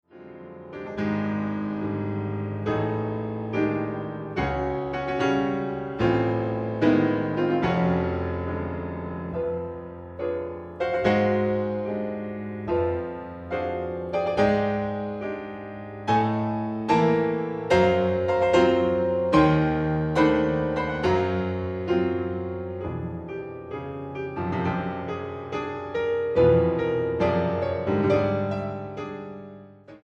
Preludios para piano 2a.